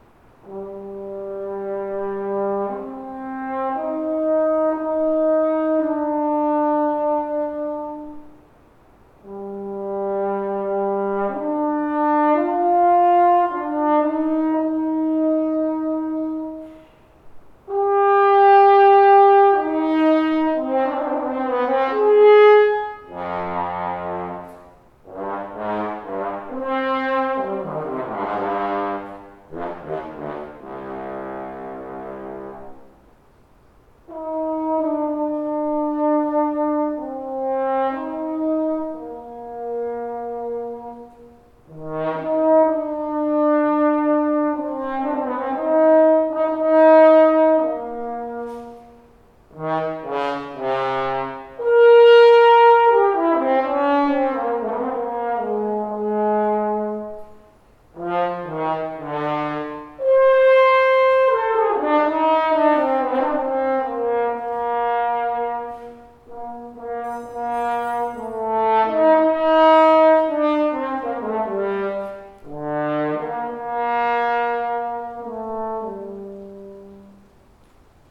I have been doing some thinking recently on this topic, and decided to record some more audio examples of the two flares I currently own for my Engelbert Schmid double horn: a lacquered, spun flare, and an unlacquered, hand hammered flare with a garland.
First, I recorded these short excerpts in a larger space, with the microphones placed approximately twenty feet away, and second I am asking readers to take a very short poll to determine which bell is generally preferred by listeners.
Please note that while every attempt was made to perform the excerpts in as consistent a manner as possible, there is the occasional “blip” as well as some extraneous noise in the hall (not created by me).
Bell 1 – Brighter Sound, liked the in forte attacks in the low range, liked the tone coulor on the high range.
Bell 1 has a more focused and stable sound which is even through each note.
hhbell_excerpt1.mp3